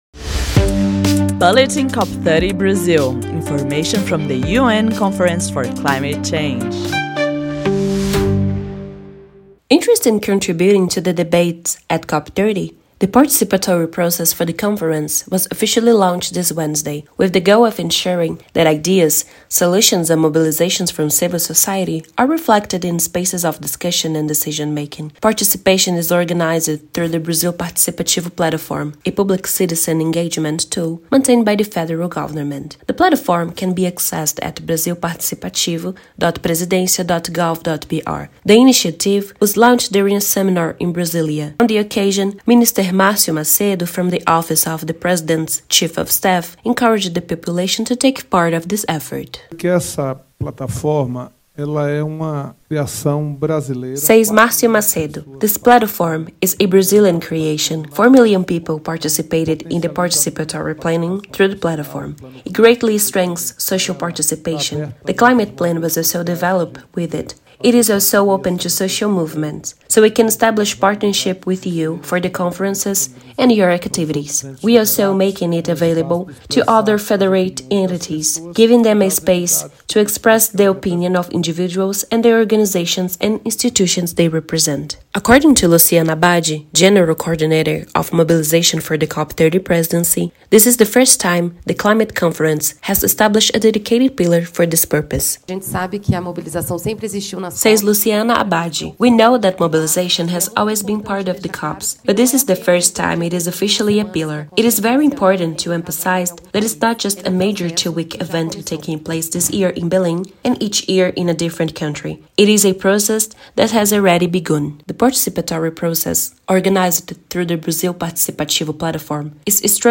Voice-over